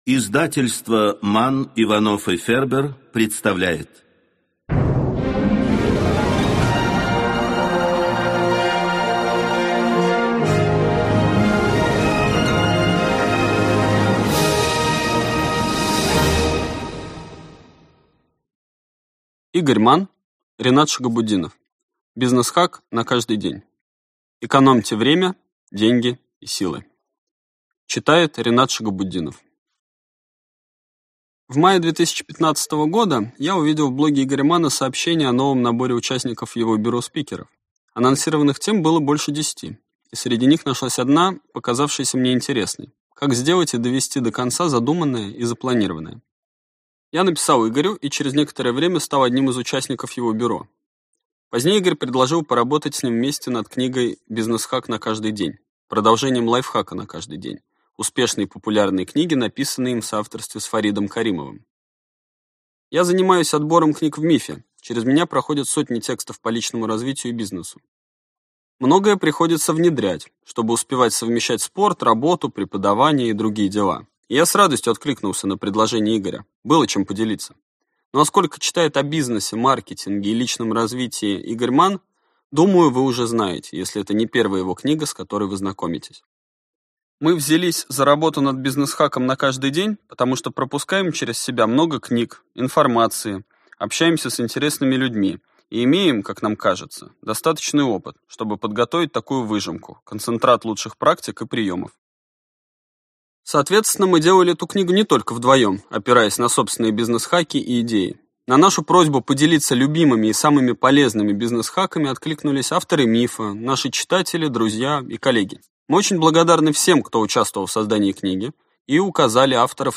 Аудиокнига Бизнесхак на каждый день. Экономьте время, деньги и силы | Библиотека аудиокниг